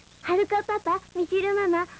Little Hotaru in the beginning of Sailor Stars addressing "Haruka-papa" and "Michiru-mama."